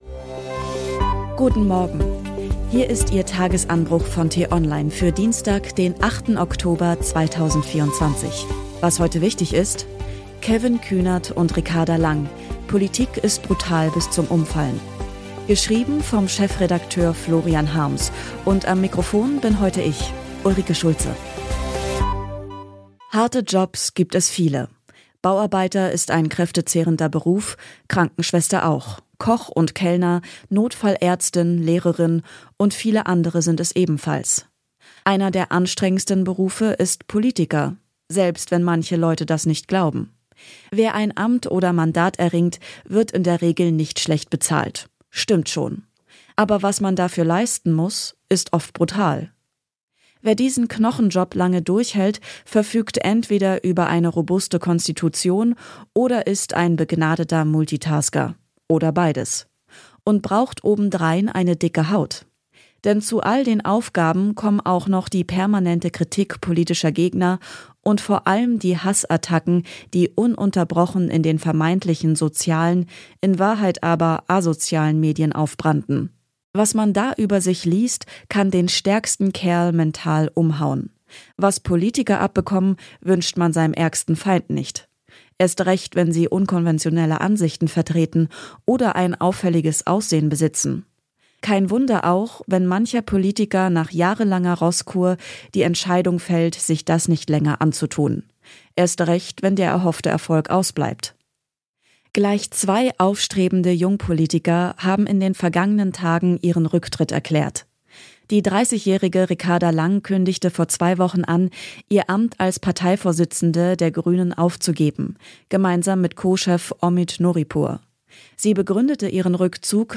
Am Wochenende geht es in einer längeren Diskussion mit prominenten Gästen um ein aktuelles, politisches Thema.